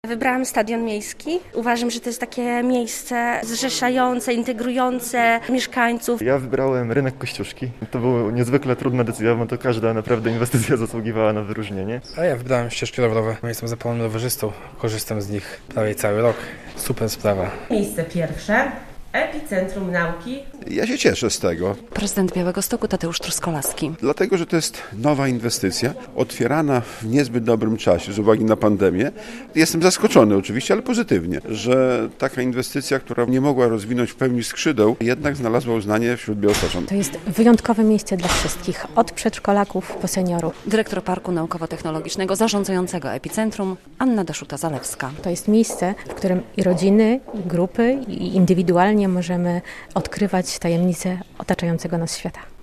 Epi-Centrum Nauki to według białostoczan najważniejsza inwestycja ostatnich 15 lat - relacja